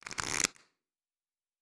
Cards Shuffle 2_07.wav